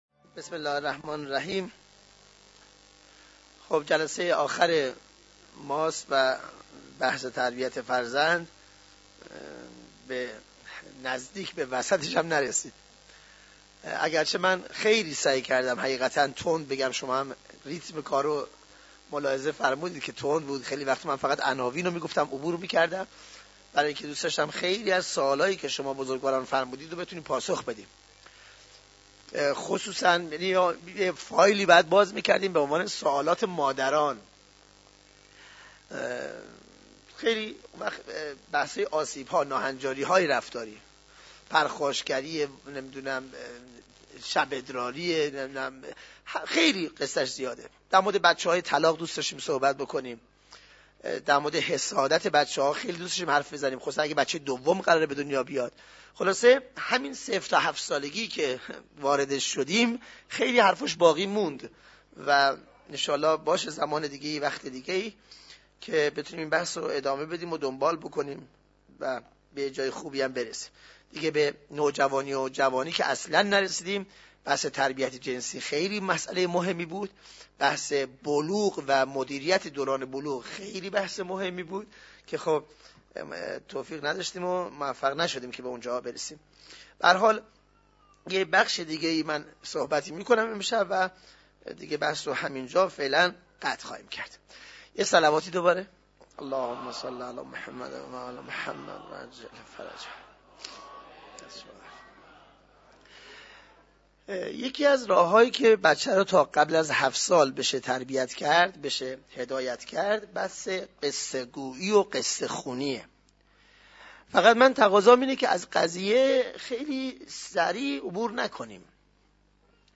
قصه گویی برای کودکان